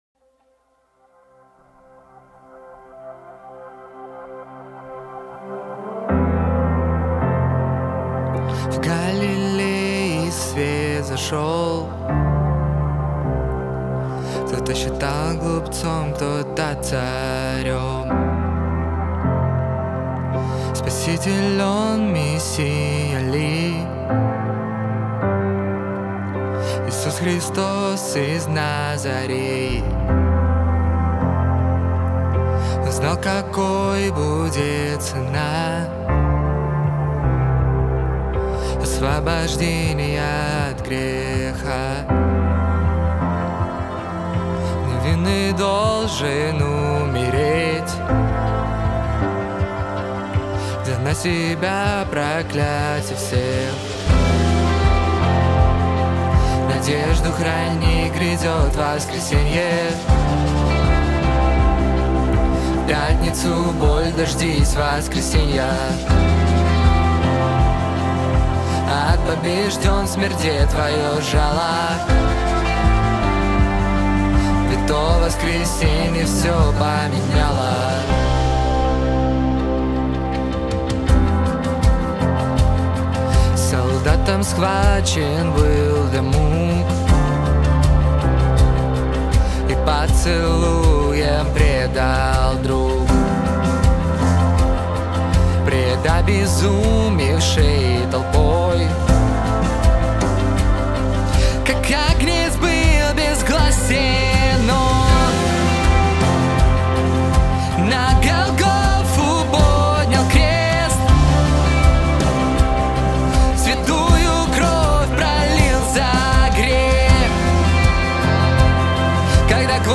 песня
312 просмотров 133 прослушивания 2 скачивания BPM: 80